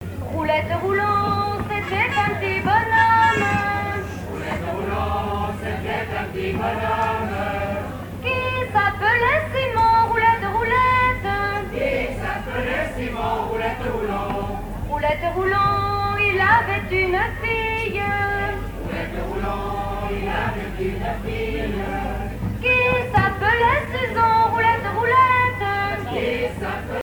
Genre laisse
Veillée à Champagné
Pièce musicale inédite